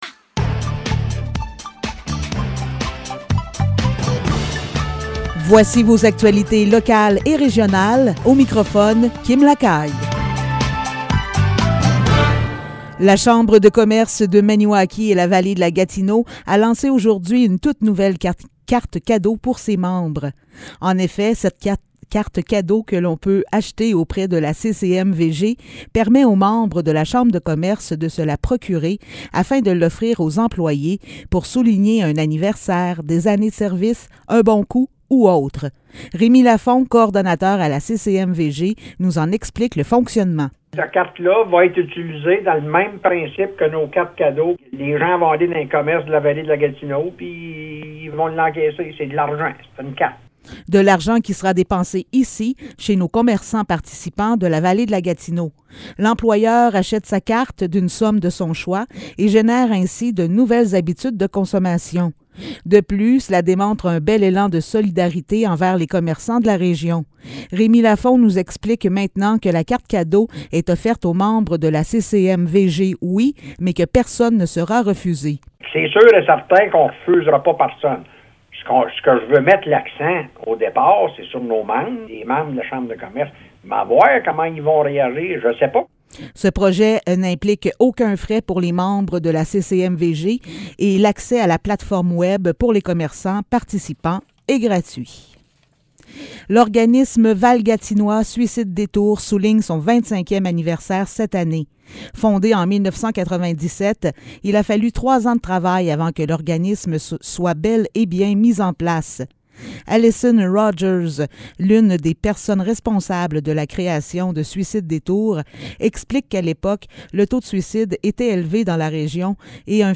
Nouvelles locales - 9 février 2022 - 15 h